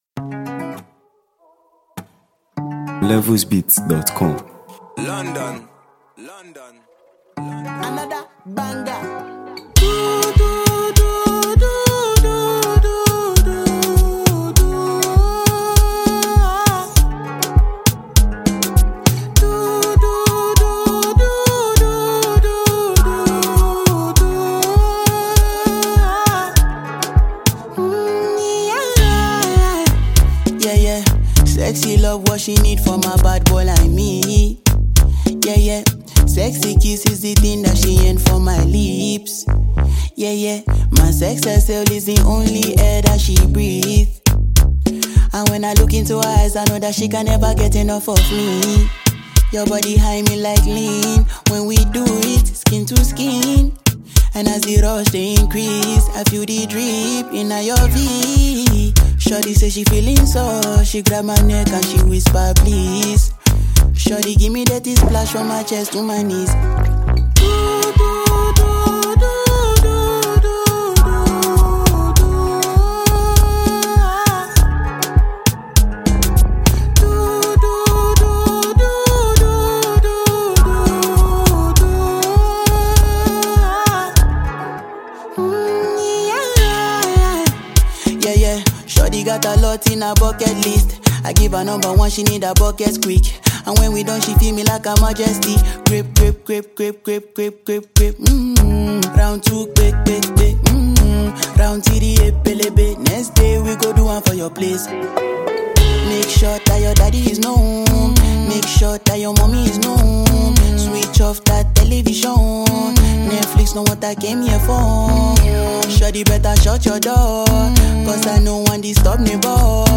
a smooth and emotionally charged record